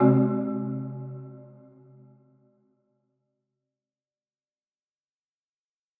Marimba Hit 1
Category: Percussion Hits
Marimba-Hit-1.wav